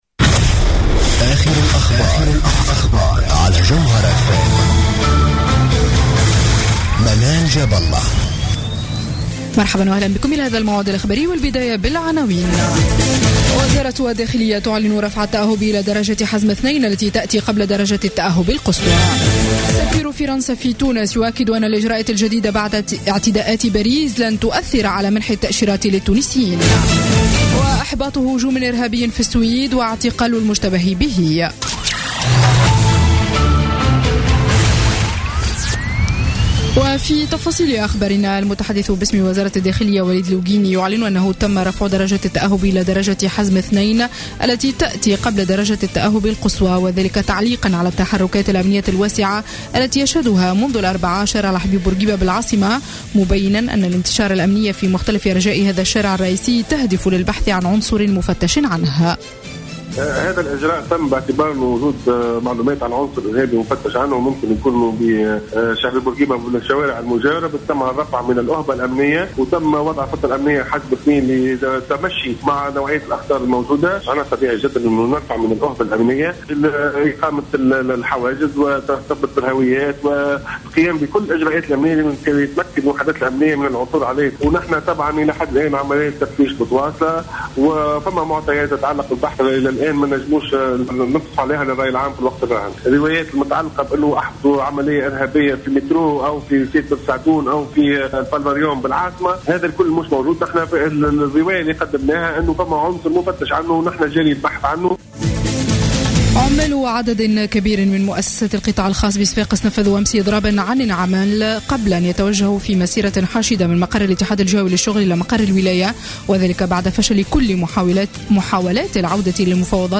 نشرة أخبار منتصف الليل ليوم الجمعة 20 نوفمبر 2015